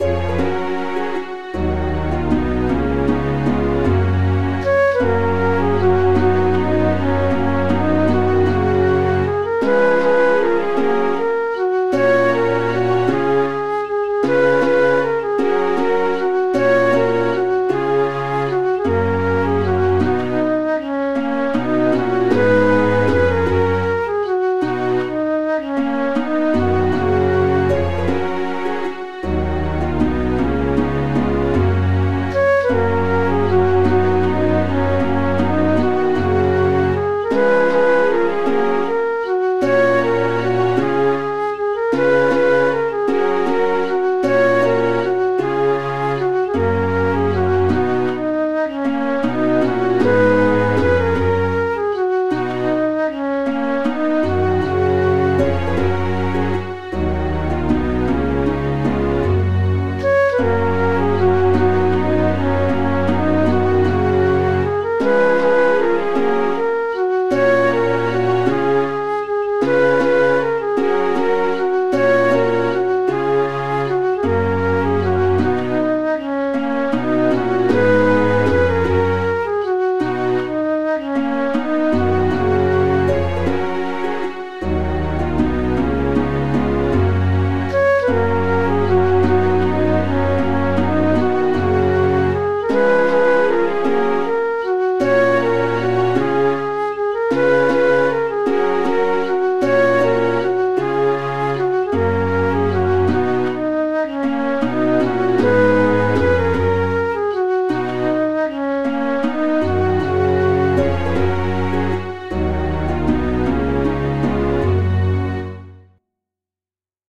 an old Irish air
meeting.mid.ogg